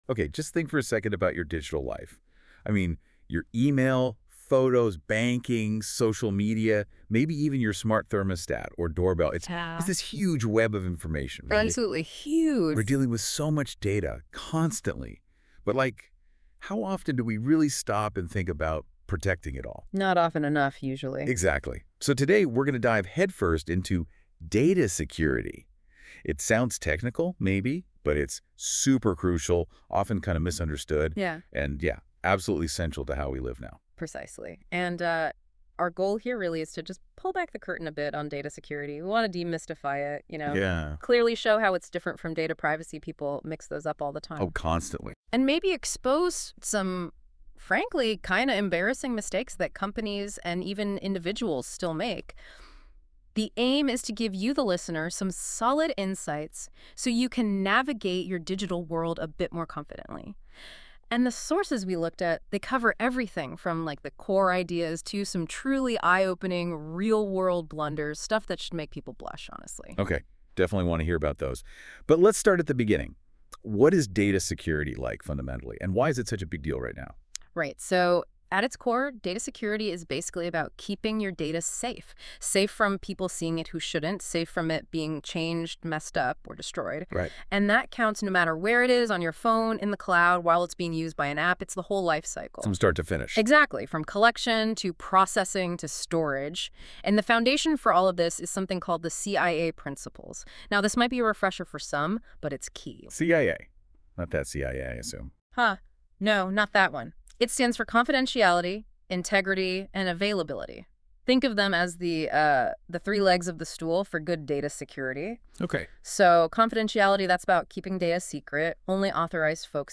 Activity: Listen to This Podcast That was created using AI from these materials.